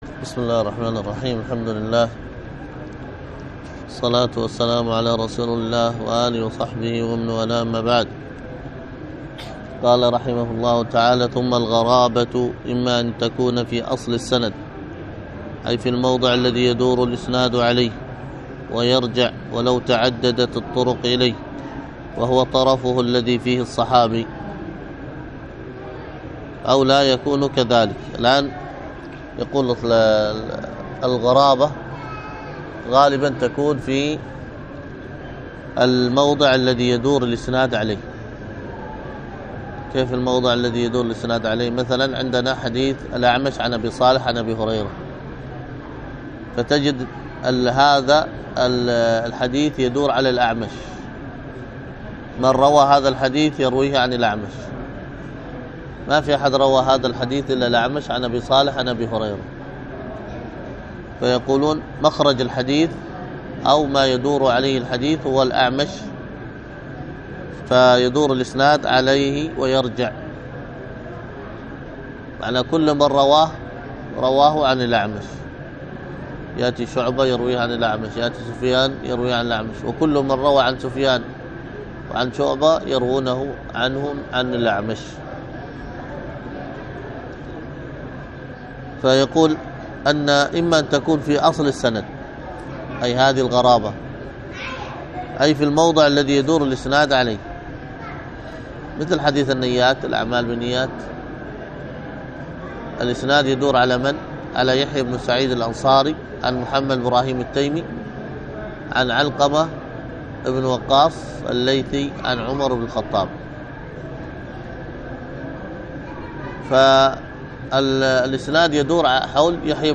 الدرس في التعليقات على شرح العقيدة الطحاوية 39، ألقاها